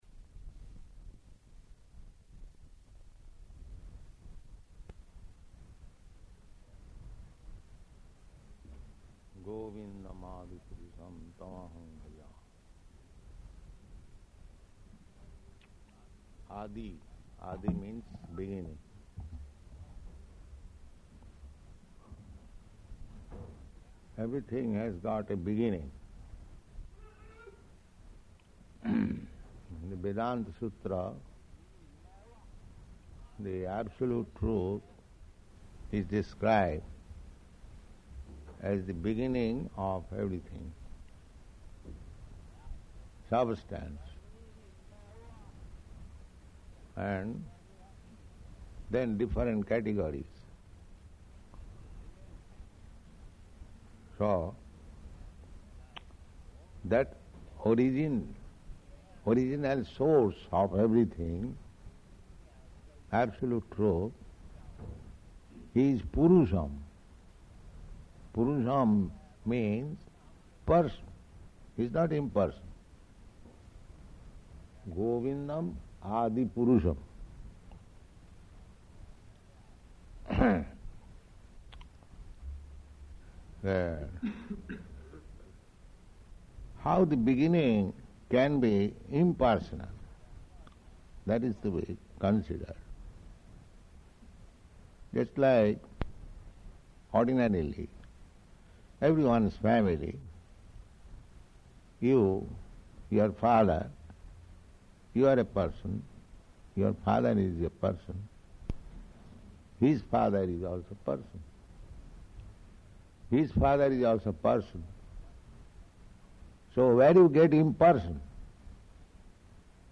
Initiation Lecture
Initiation Lecture --:-- --:-- Type: Initiation Dated: July 5th 1971 Location: Los Angeles Audio file: 710703IN-LOS_ANGELES.mp3 Prabhupāda: Govindam ādi-puruṣaṁ tam ahaṁ bhajāmi.